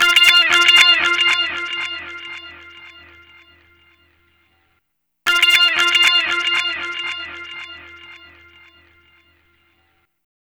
Ala Brzl 2 Eko Gtr-F.wav